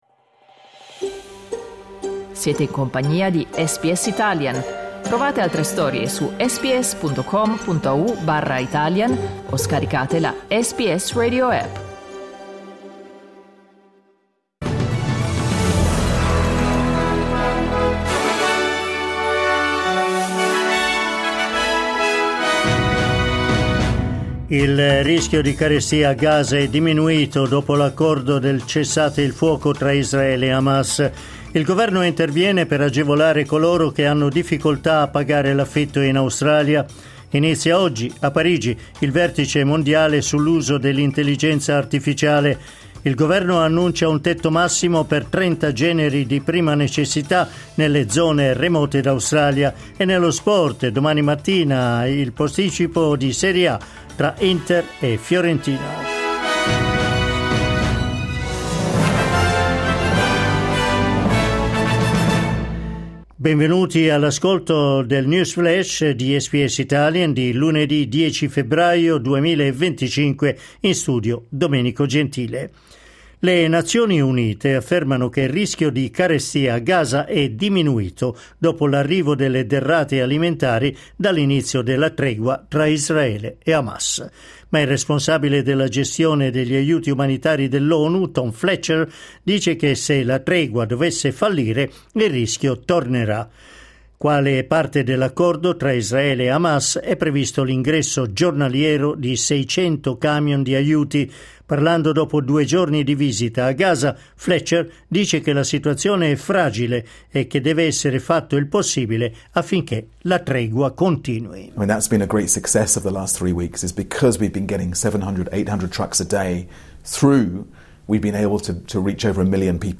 L’aggiornamento delle notizie di SBS Italian.